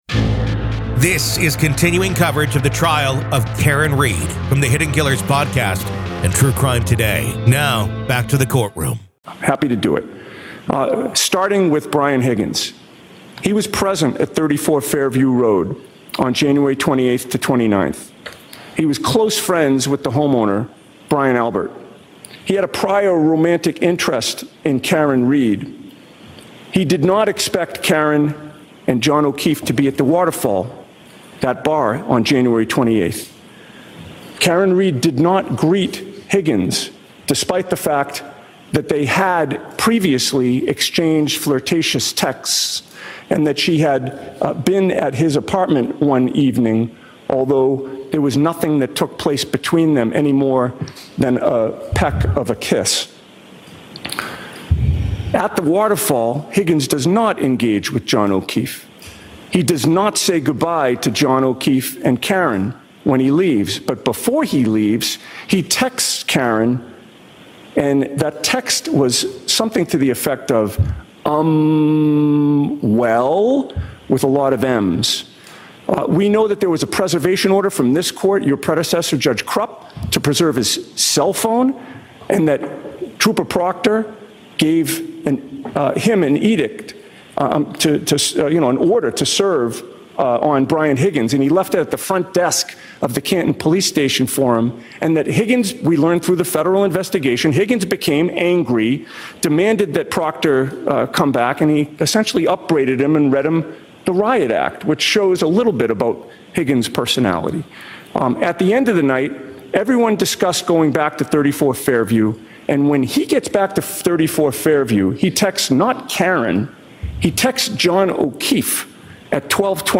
The Trial Of Karen Read | Pretrial Hearing Part 4 of 4
Welcome to a special episode of "The Trial of Karen Read," where today, we find ourselves inside the courtroom for a critical pre-trial hearing in the case against Karen Read.